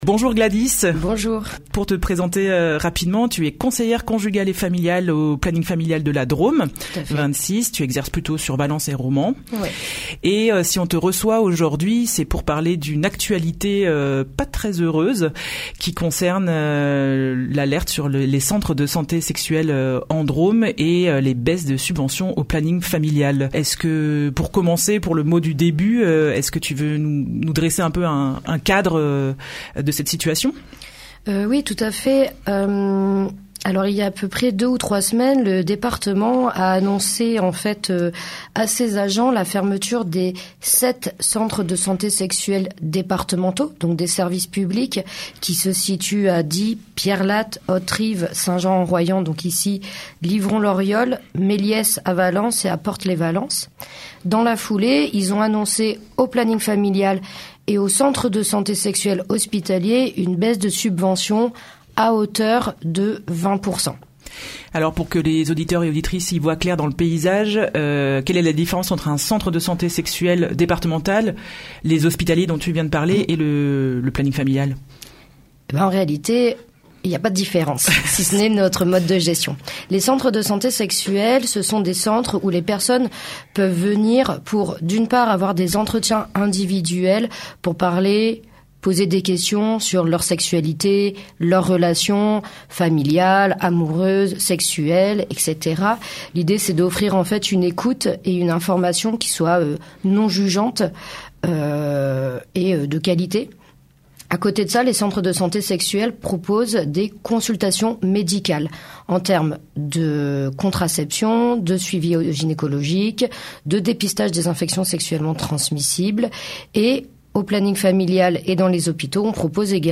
en direct dans la matinale